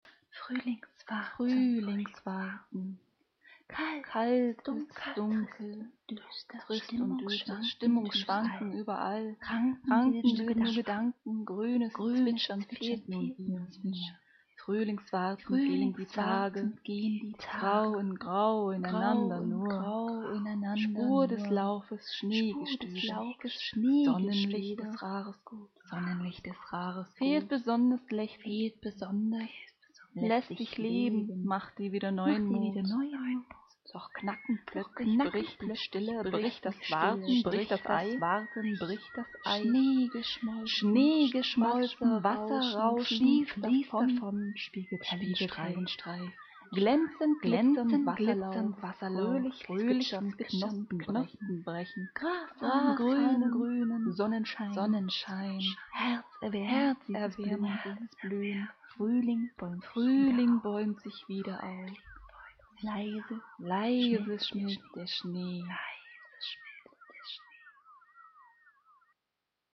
..ja auch das zwitschern hab ich hier übernommen...;D